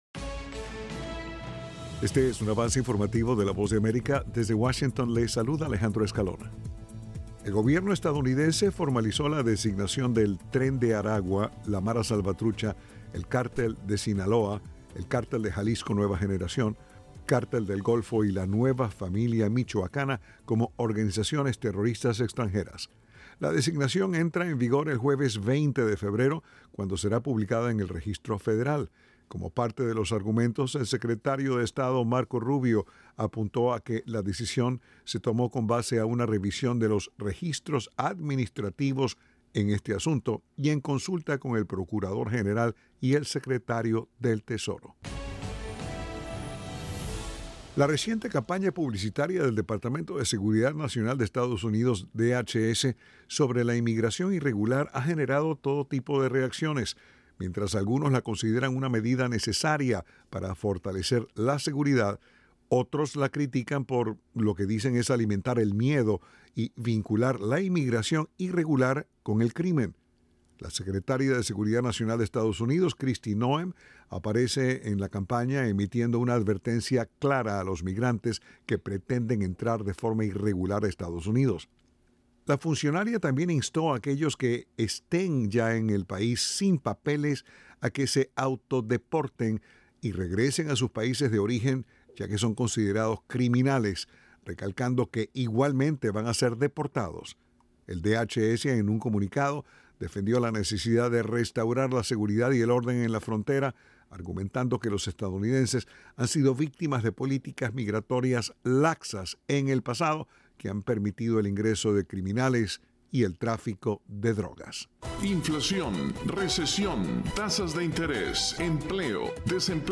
El siguiente es un avance informativo de la Voz de América.